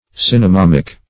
Cinnamomic \Cin`na*mom"ic\